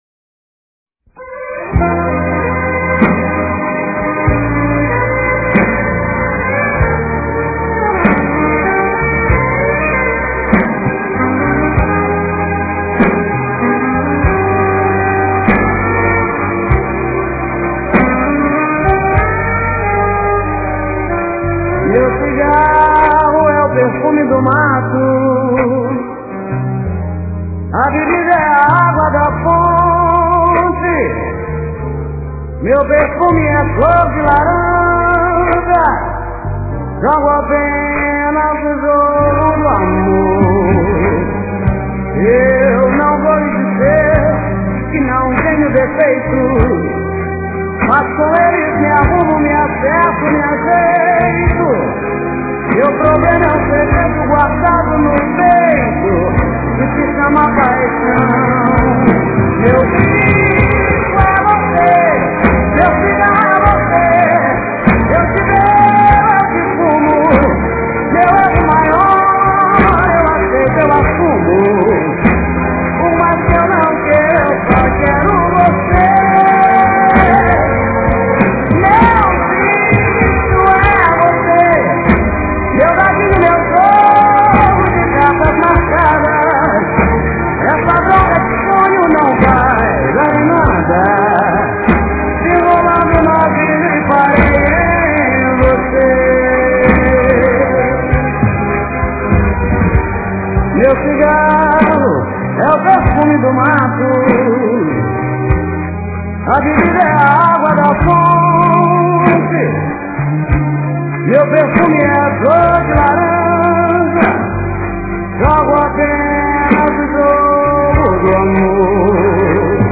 no final tem um Link para Abrir a Música que é Cantada.